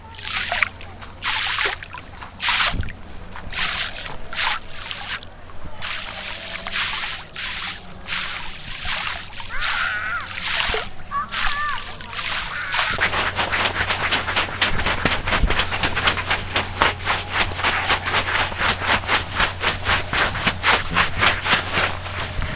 모래차기.mp3